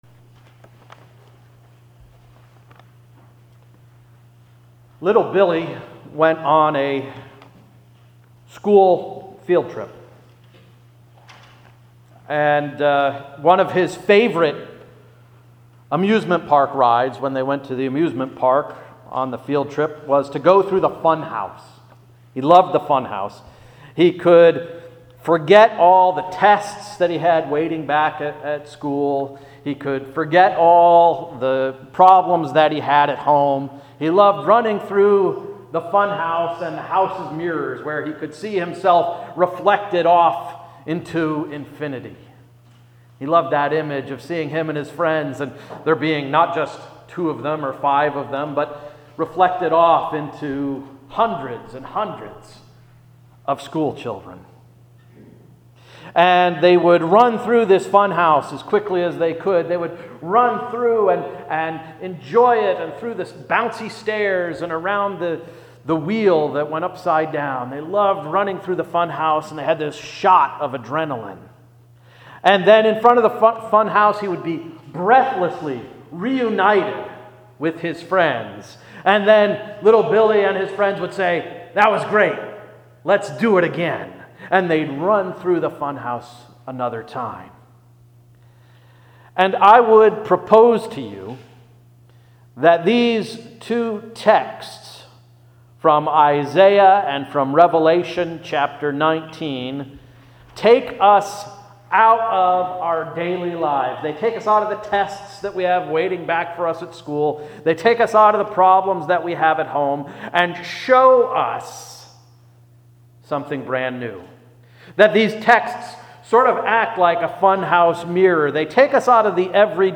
November 6, 2016 Sermon — “Beauty from Brokenness”